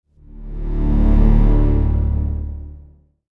Sci-Fi Dramatic Transition Sound Effect
This sci-fi dramatic transition sound effect delivers futuristic tones and powerful energy.
Use it to create smooth, impactful, and dramatic scene transitions.
Genres: Sound Effects
Sci-fi-dramatic-transition-sound-effect.mp3